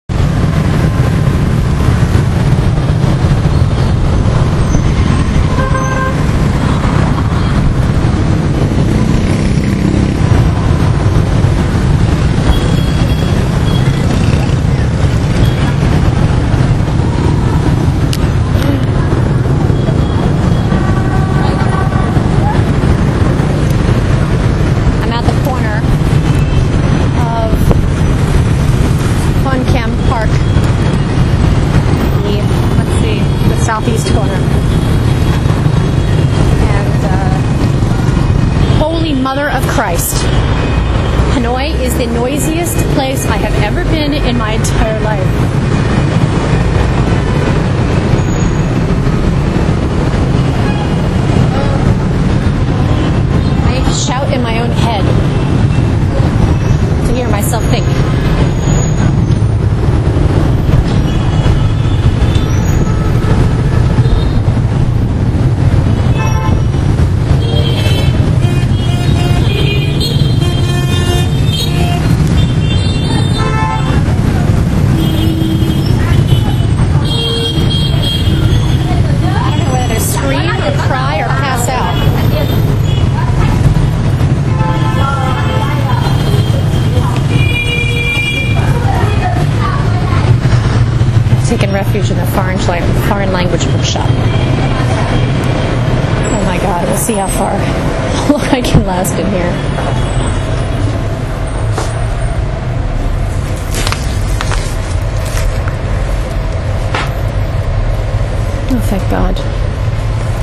Hanoi Noise short
HANOI IS ASTONISHINGLY LOUD.
MOST OF MY THOUGHTS HAVE BEEN ERADICATED BY THE DRONE OF MOTORBIKES. IT’S LIKE VIETNAM HAS BEEN INVADED BY GIANT MUTANT KILLER BEETLES.
hanoi-noise-2.WMA